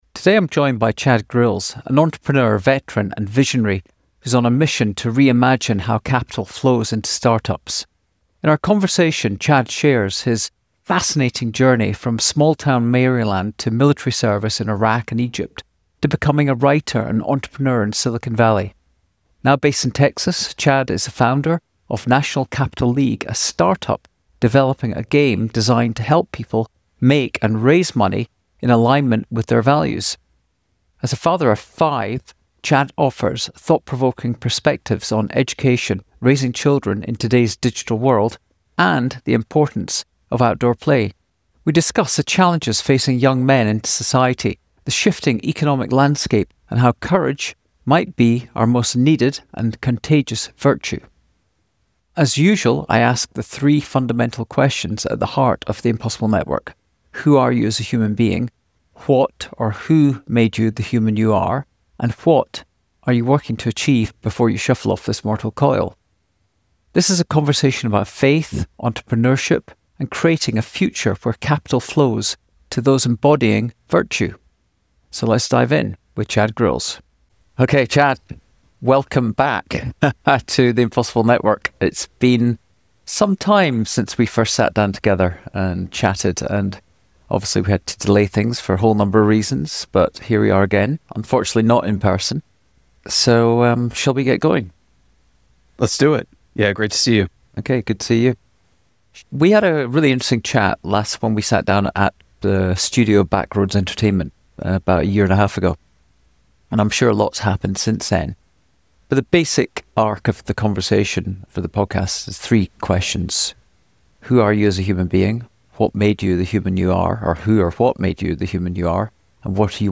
Ep 260: Building a New Game for Capitalism - A Conversation